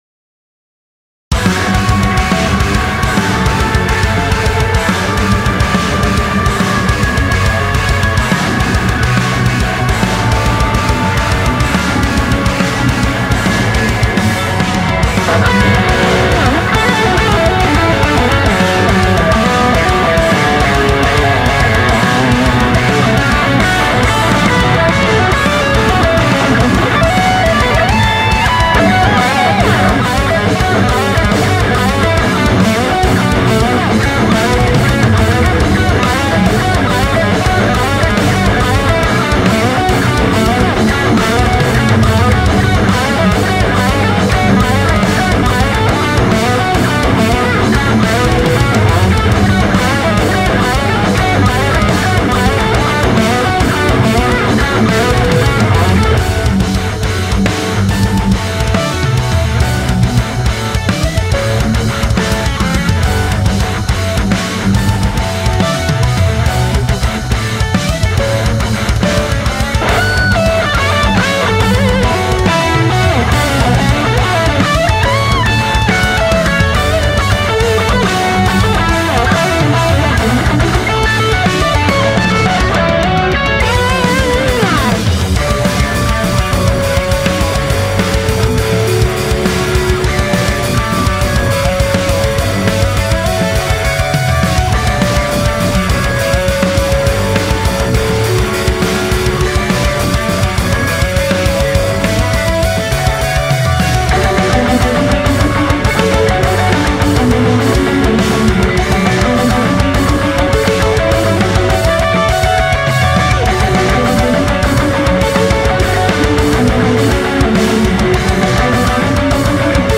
Percussion
Tenor Saxophone